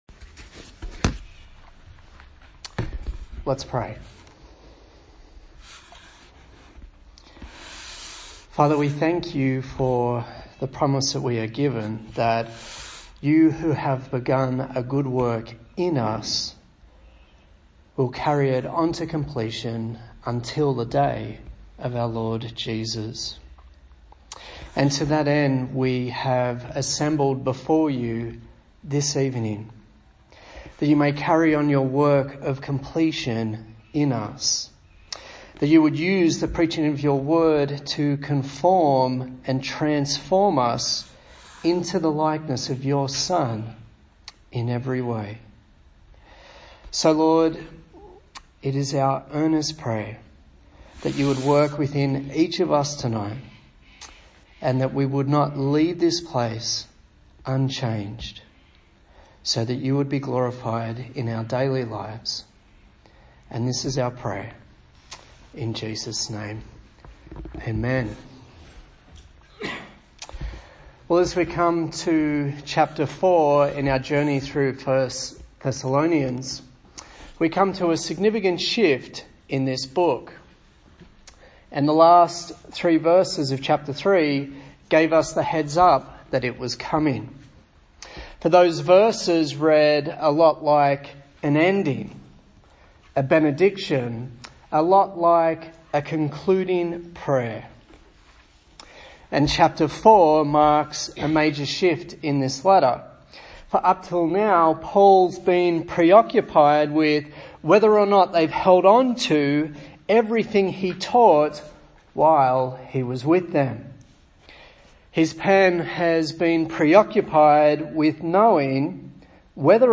A sermon on the book of 1 Thessalonians